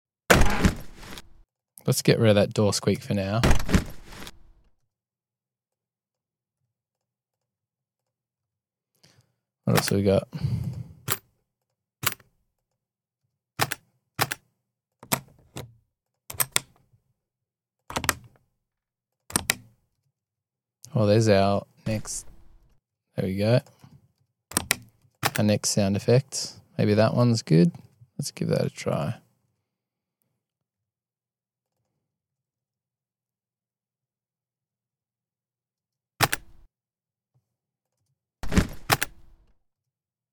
Sound Design Demo with Pro sound effects free download